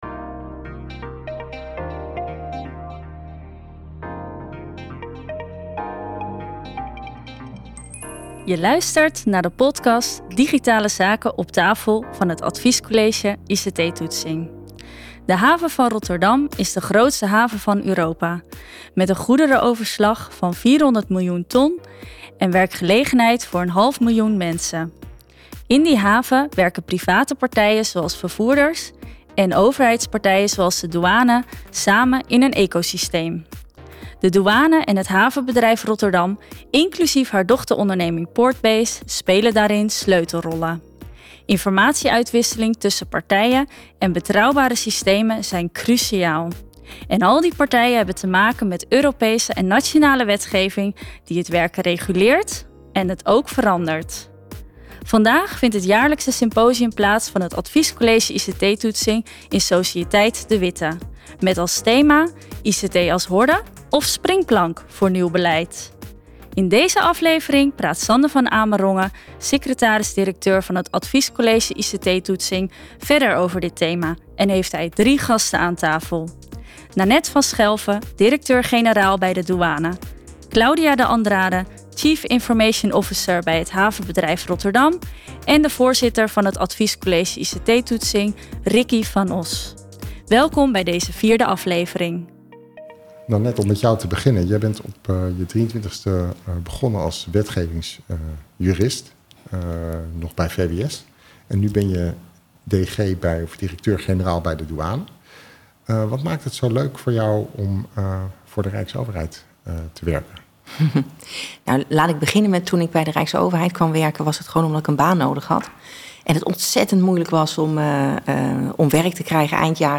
♪ LICHT OPZWEPEND DEUNTJE ♪ [Logo Adviescollege ICT-toetsing.